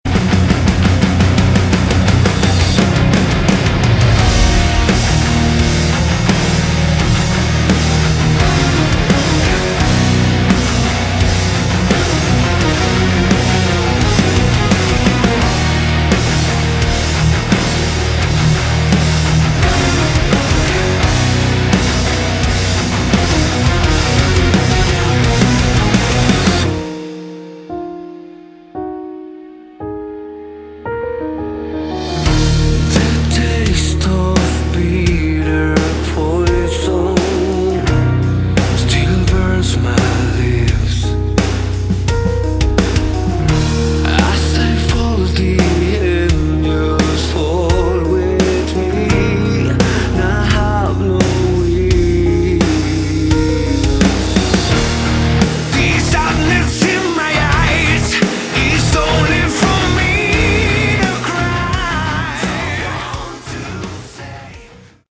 Category: Melodic Metal
Vocals
Guitar, vocals, keyboards
Bass, vocals
Drums